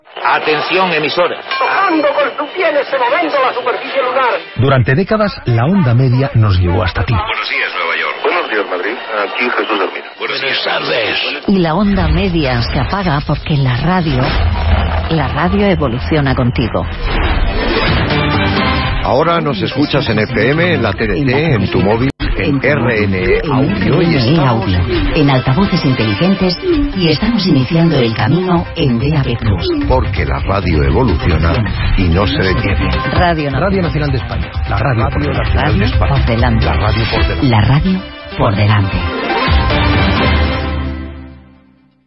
Un anunci de l'emissora pública RNE (Radio Nacional de España)